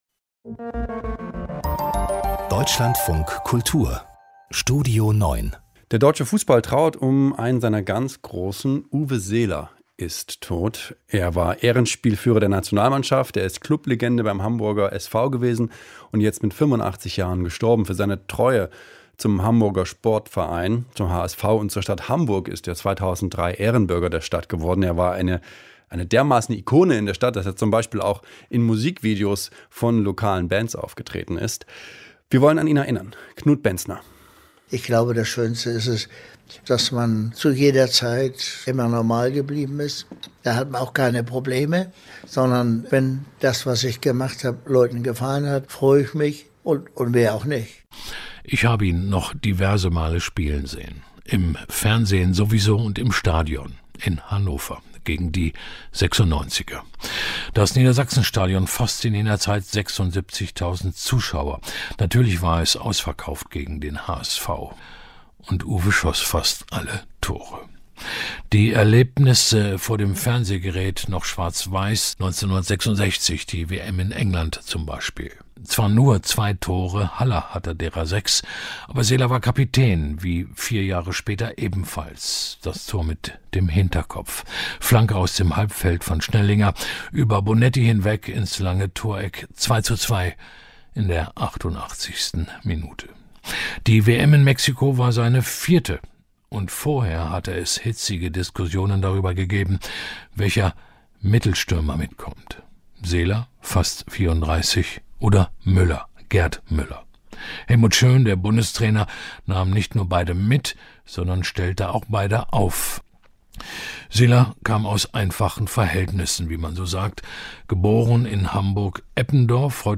Nun ist Uwe Seeler mit 85 Jahren gestorben, wie sein früherer Verein der Hamburger SV unter Berufung auf die Familie bestätigte. Ein Nachruf